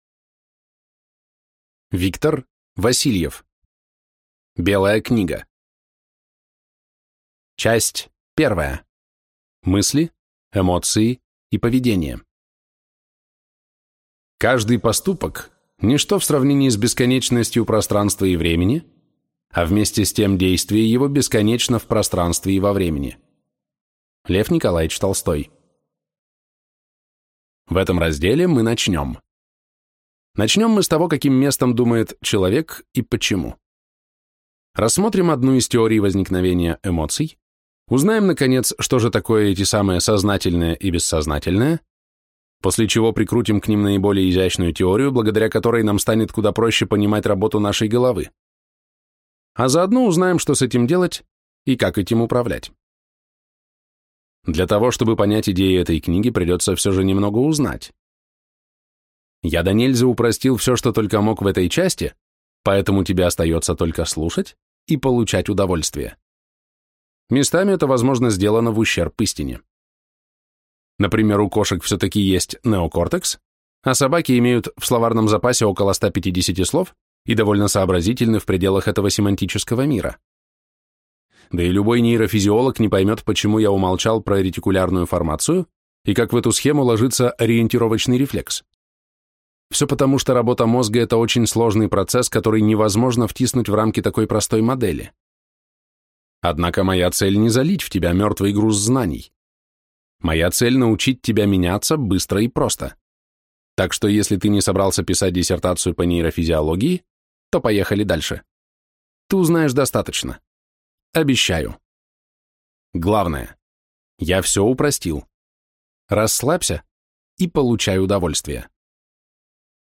Аудиокнига Белая книга | Библиотека аудиокниг